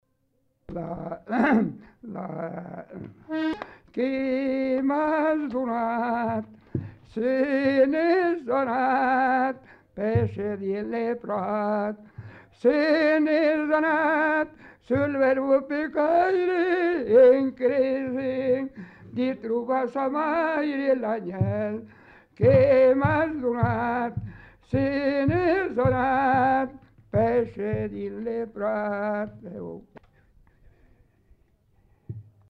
Genre : chant
Effectif : 1
Type de voix : voix d'homme
Production du son : chanté